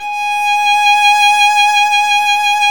Index of /90_sSampleCDs/Roland - String Master Series/STR_Violin 2&3vb/STR_Vln2 _ marc
STR  VL G#6.wav